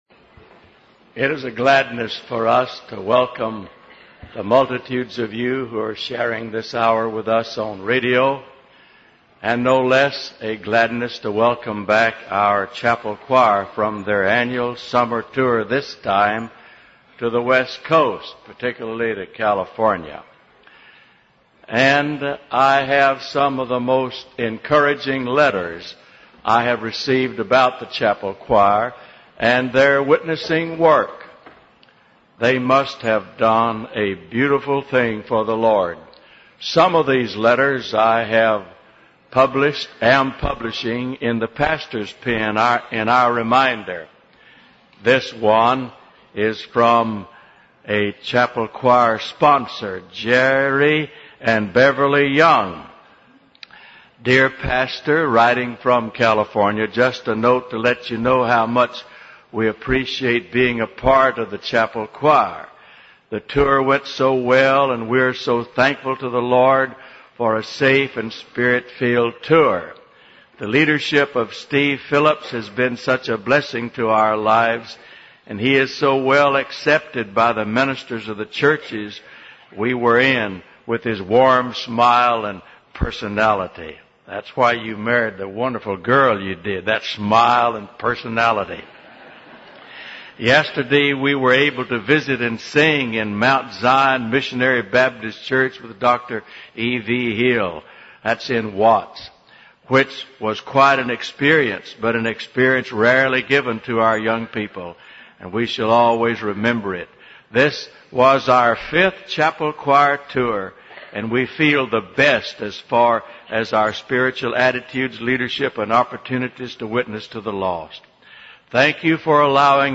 The Declaration of Righteousness – W. A. Criswell Sermon Library